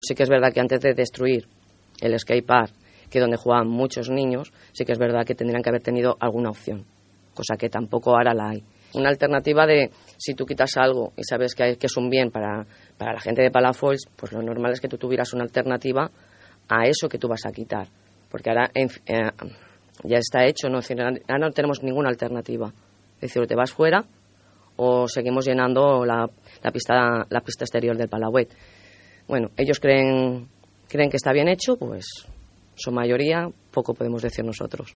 La regidora portaveu de Palafolls en Comú, Clara Hidalga, ha passat aquesta setmana pels micròfons de Ràdio Palafolls, on ha parlat sobre la situació política actual del nostre municipi.